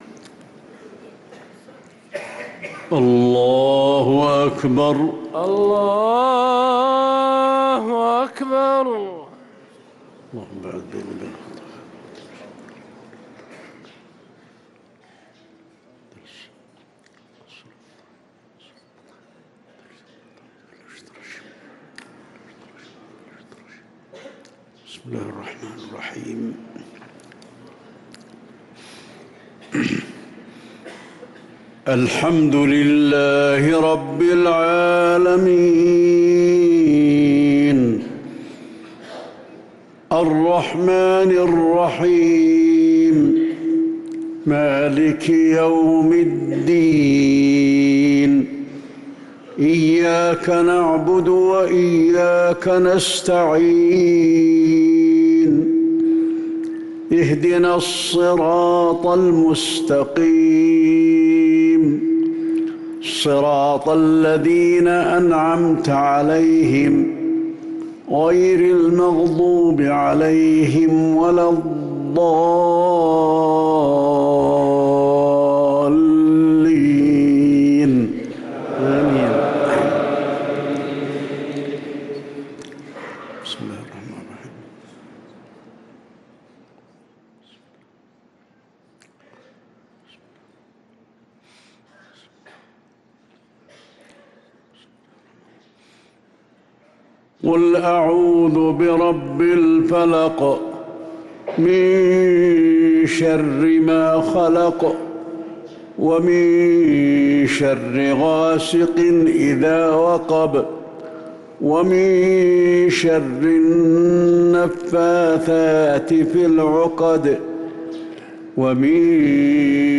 صلاة المغرب للقارئ علي الحذيفي 5 شوال 1444 هـ